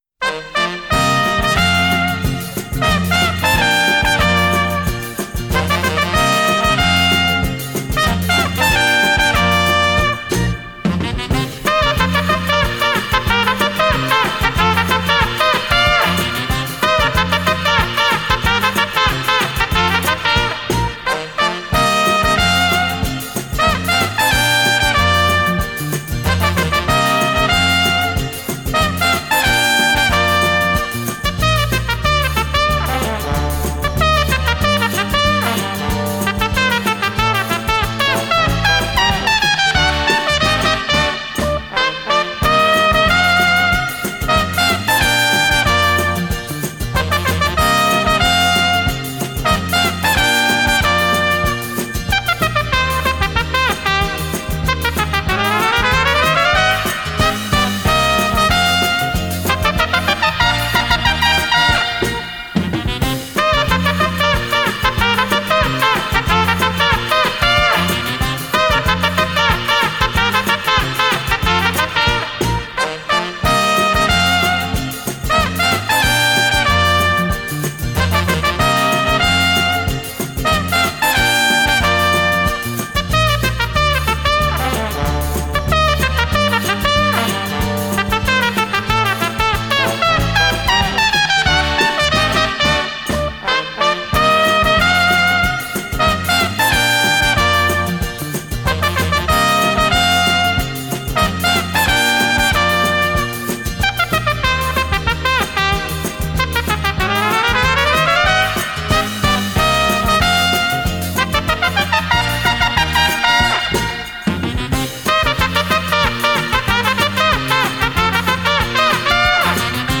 Французский трубач, руководитель оркестра.